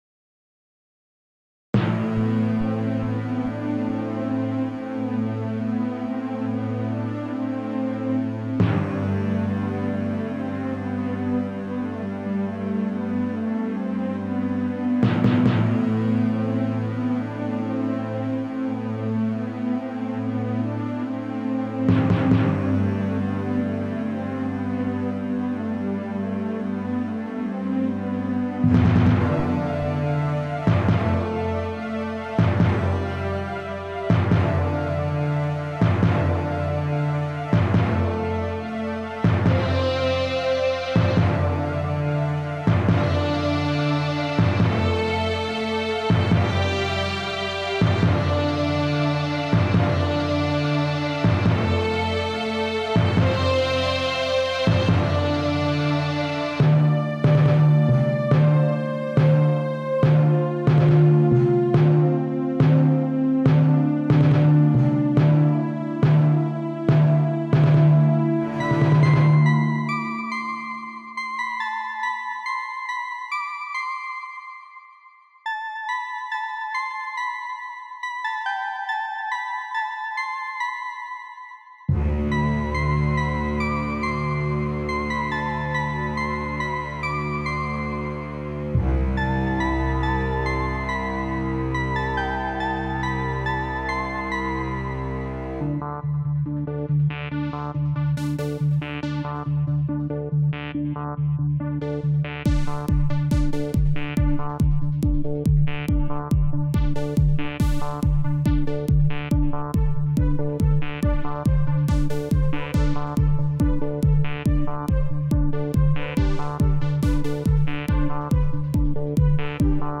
The instruments used here were: Sytrus Acid, Orchestral Poizone ambient pad Virtual kicklab of Angular Momentum GTG K1.3 Synthetizer Reason Orchestral.
synth orchestral